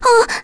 Ophelia-vox-Sur1_kr.wav